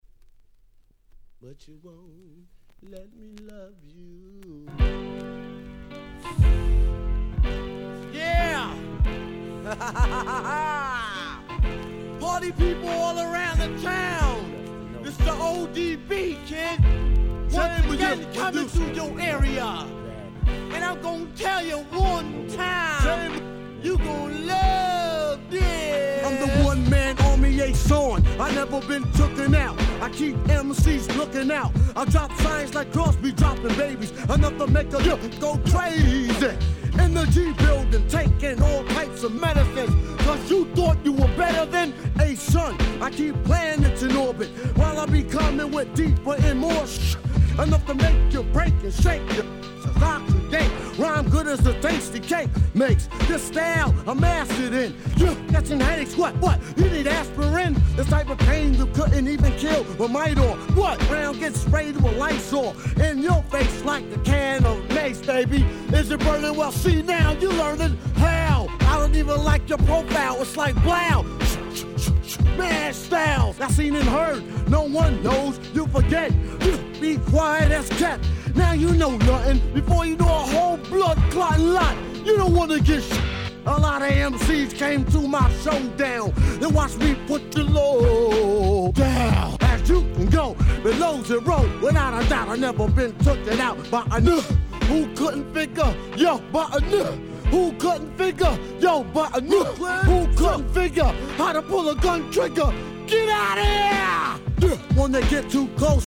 これぞ90's Hip Hop !!